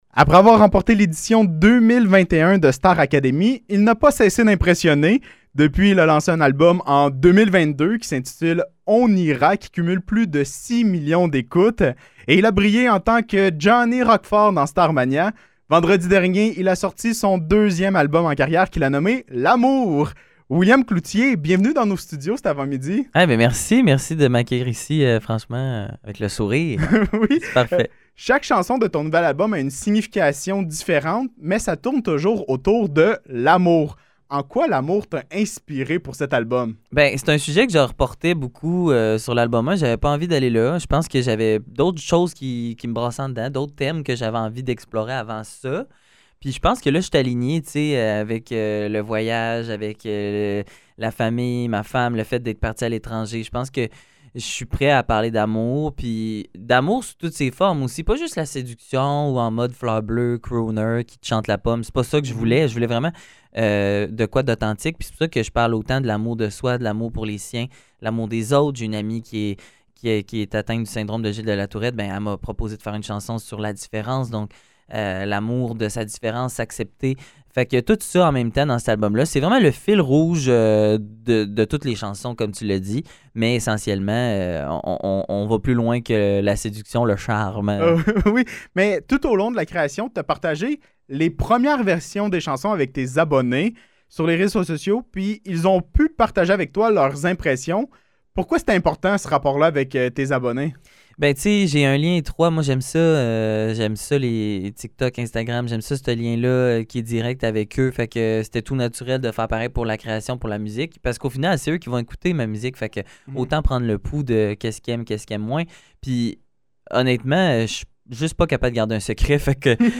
Entrevue avec William Cloutier
Entrevue avec William Cloutier qui était de passage dans nos studios pour nous parler de son nouvel album L’amour, de sa carrière depuis Mixmania et de son retour à Star Académie dimanche dernier.
ENTREVUE-WILLIAM-CLOUTIER-WEB.mp3